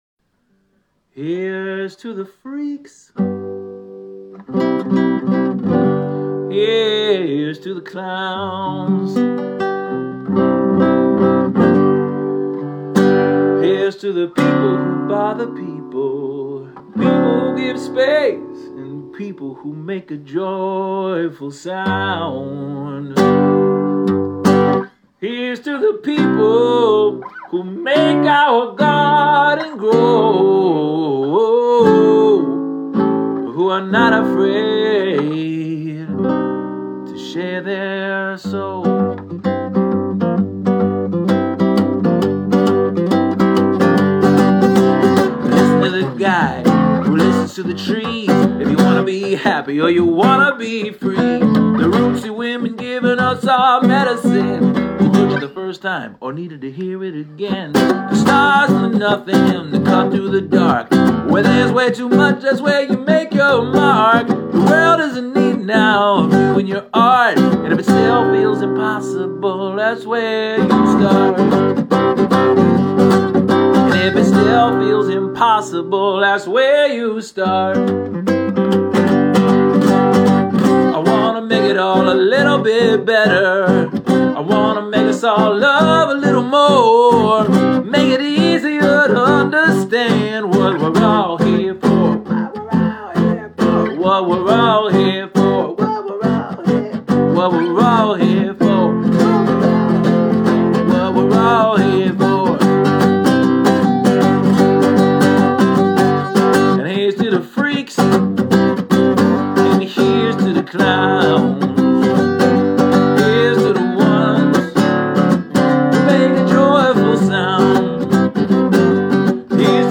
I wanted to write a jam.
I LOVE the music/melody/guitar!